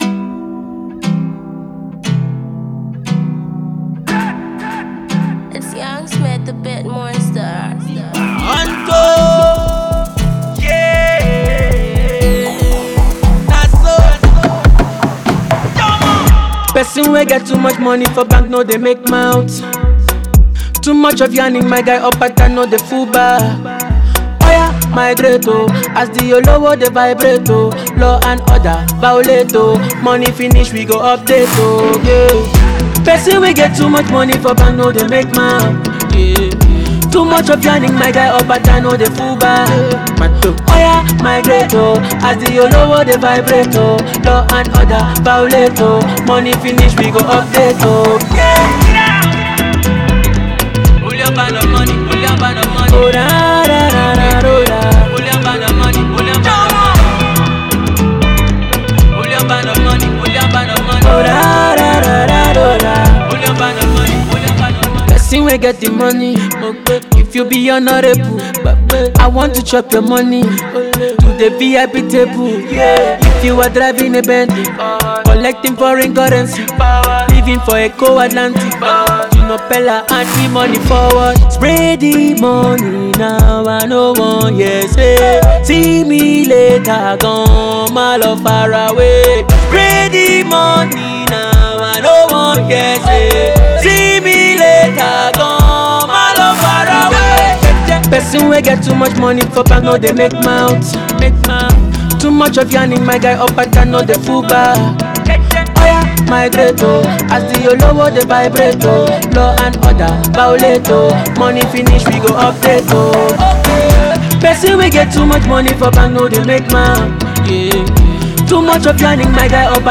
afrosonic pop single